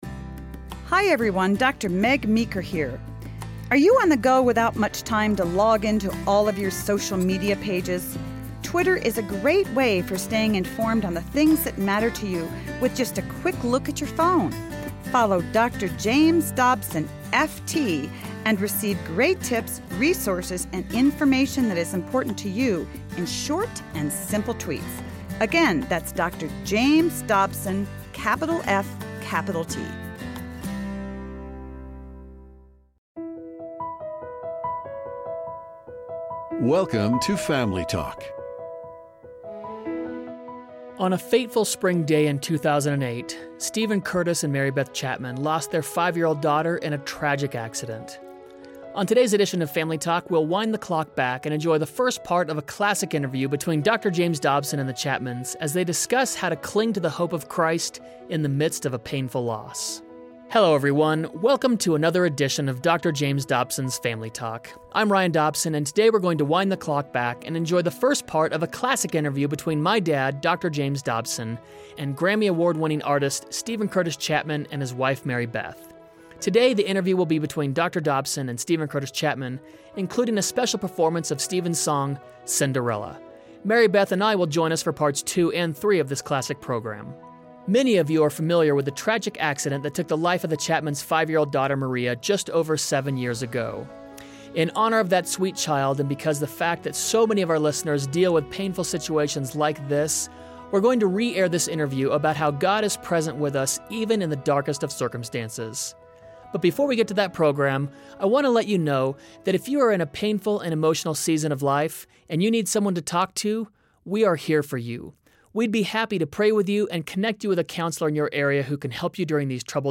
On today’s edition of Family Talk, we will wind back the clock and enjoy a classic interview between Dr. James Dobson and Steven Curtis and Mary Beth Chapman, as they discuss how to cling to the hope of Christ in the midst of a painful loss.